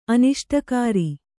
♪ aniṣṭakāri